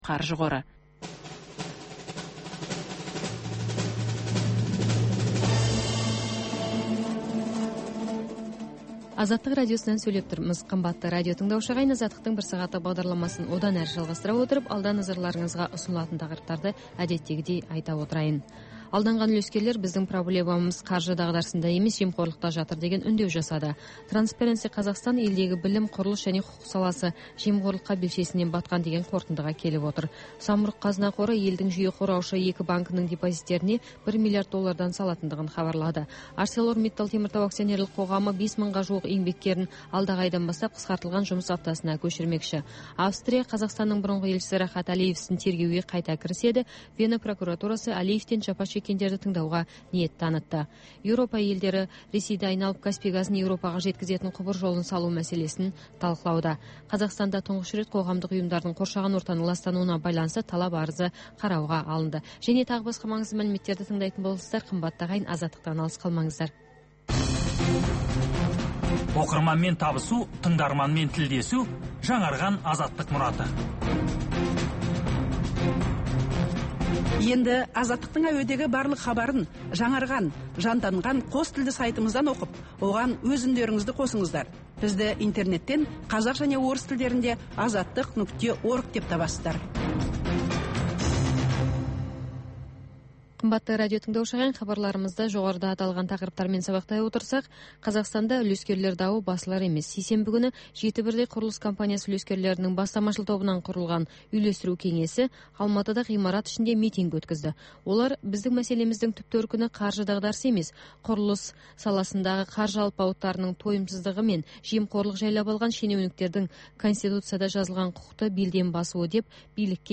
Бүгінгі күннің өзекті тақырыбына талқылаулар, оқиға ортасынан алынған репортаж, пікірталас, қазақстандық және халықаралық сарапшылар пікірі.